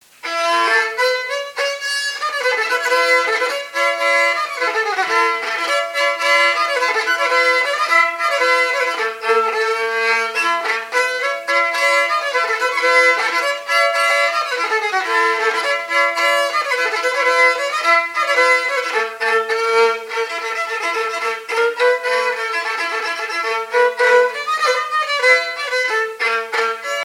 Vendée (Plus d'informations sur Wikipedia)
Fonction d'après l'analyste danse : quadrille : pas d'été ;
Catégorie Pièce musicale inédite